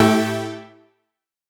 Index of /musicradar/future-rave-samples/Poly Chord Hits/Straight
FR_SOBX[hit]-A.wav